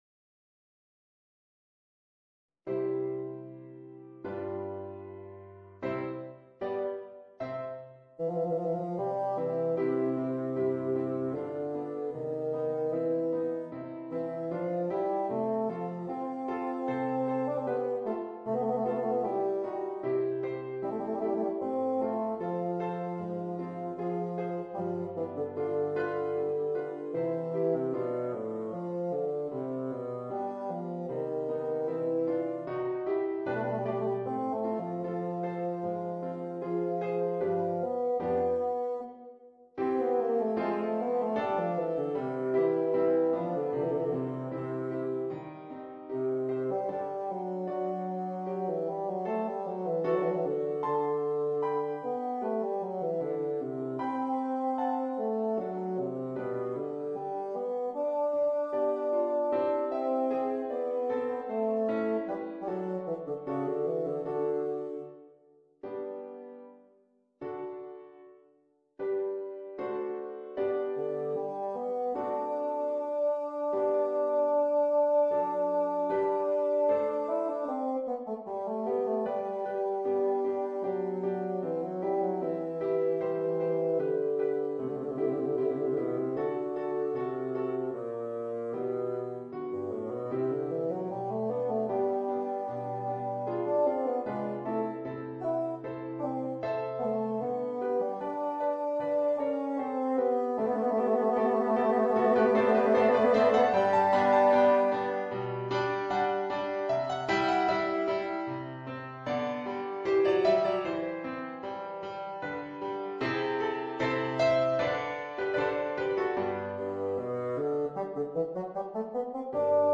Voicing: Bassoon and Piano